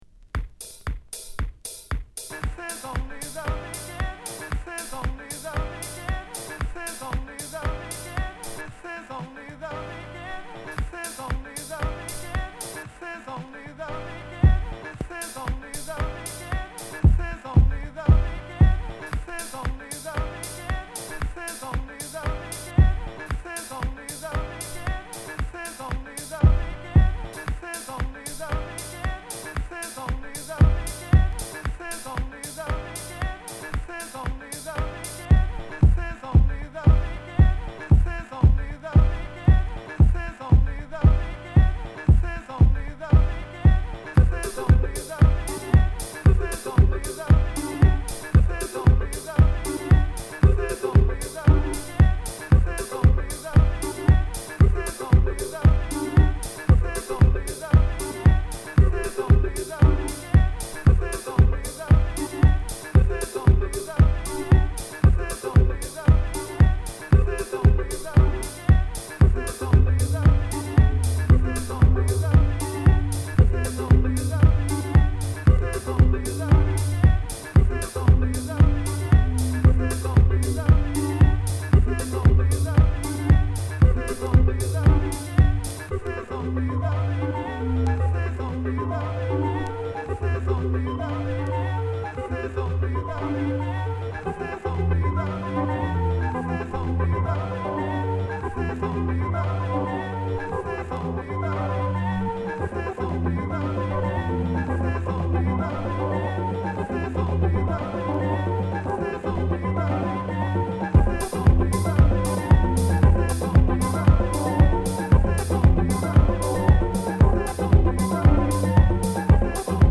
Detroit House